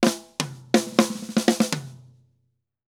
Drum_Break 120_2.wav